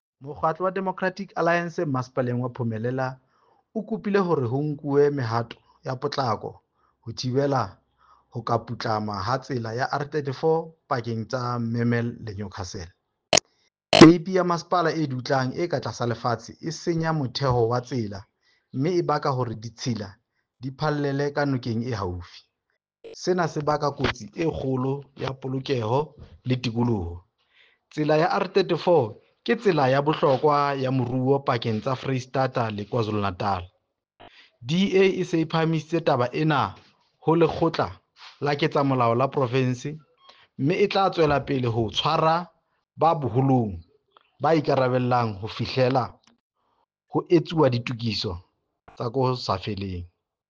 Sesotho soundbite by Cllr Diphapang Mofokeng.